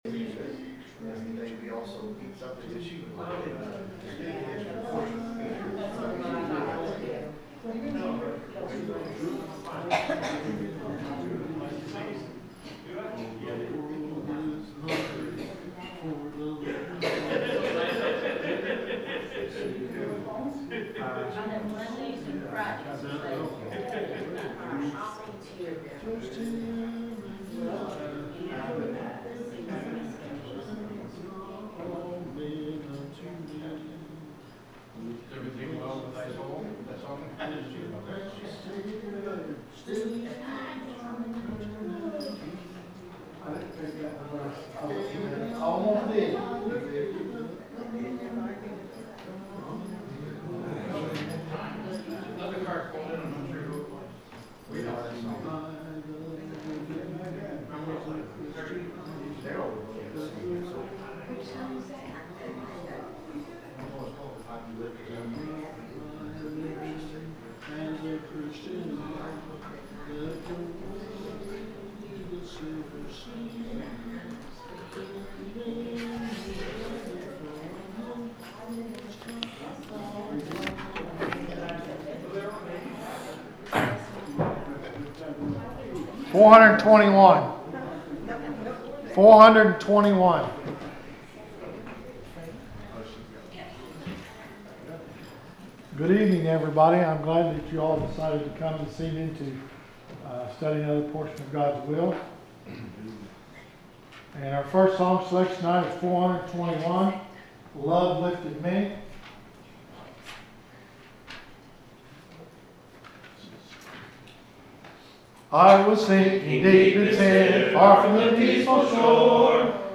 The sermon is from our live stream on 11/5/2025